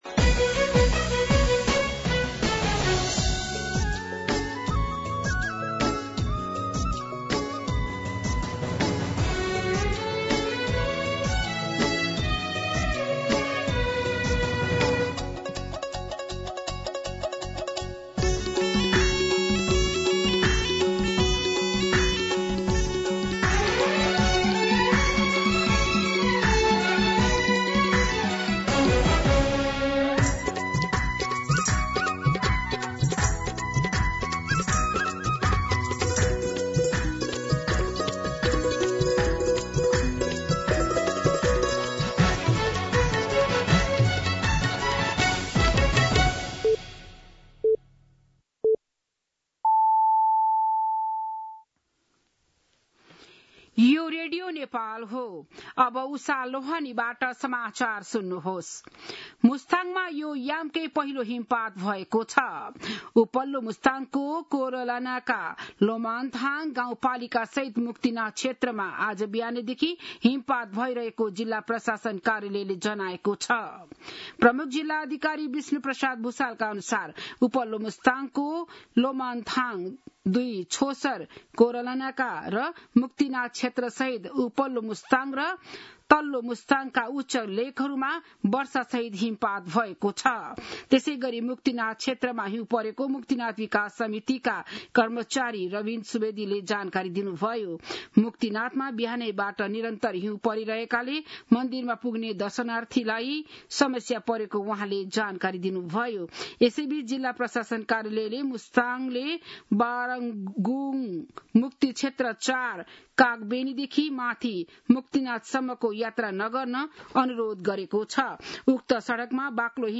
बिहान ११ बजेको नेपाली समाचार : ११ कार्तिक , २०८२